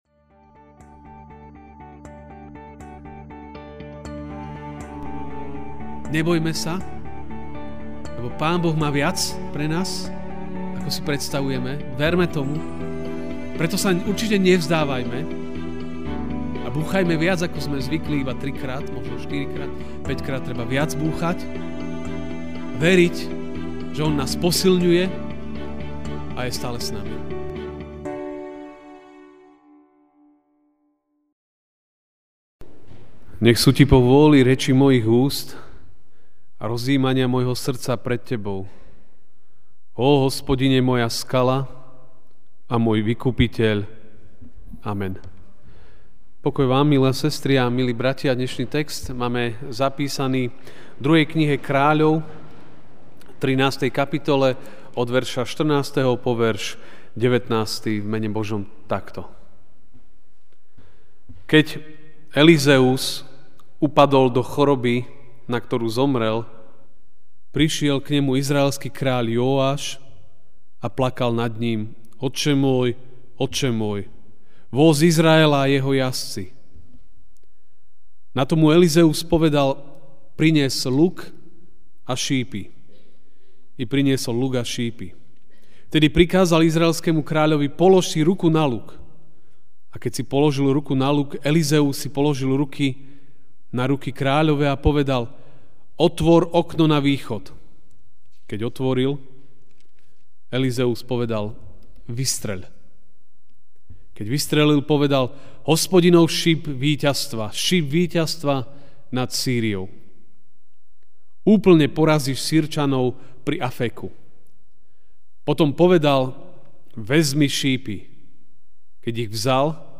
Ranná kázeň: Buďme odvážni a vytrvalí (2 Kr. 13, 14-19) Keď Elízeus upadol do choroby, na ktorú zomrel, prišiel k nemu izraelský kráľ Jóáš a plakal nad ním: Otče môj, otče môj!